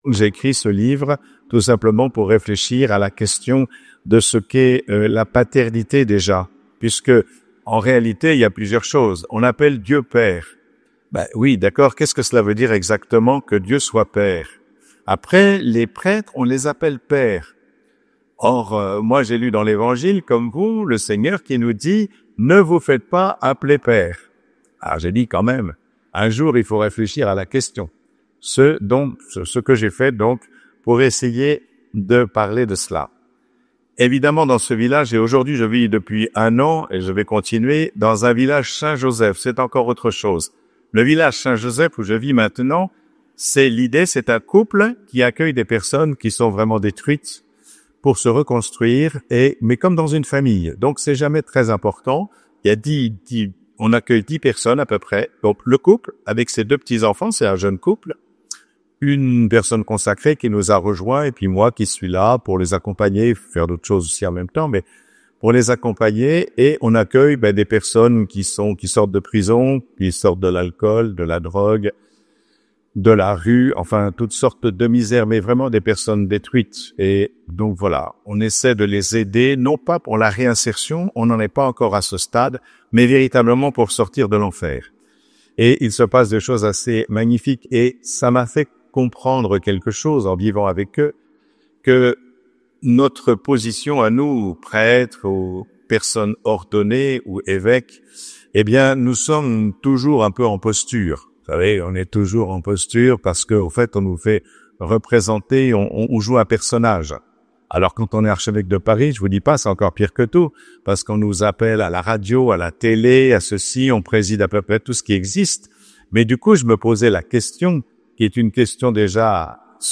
Sables d'Olonne. Conf juillet 2024 avec Mgr Michel Aupetit